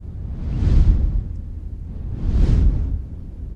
dragonstartingtofly.ogg